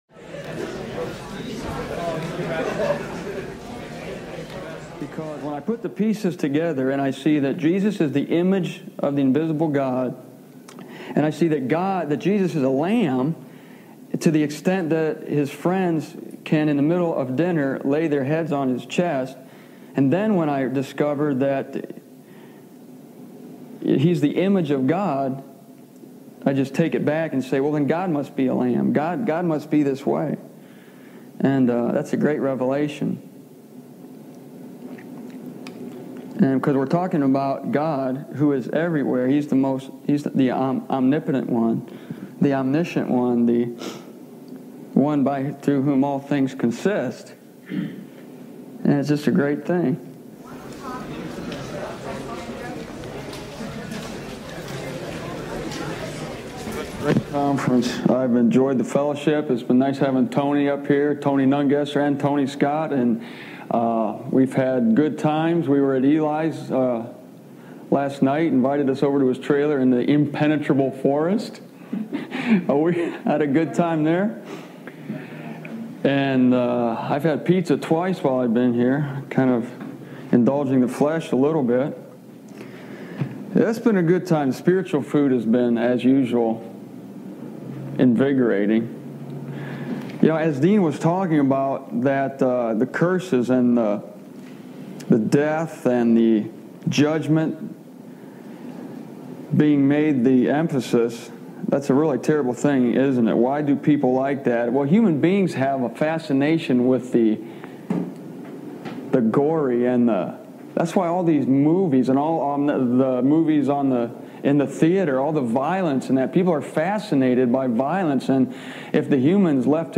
He reveals His true heart—Who He truly is—through His Son Jesus Christ. I recorded this wonderful audio in 1998 in Almont, Michigan.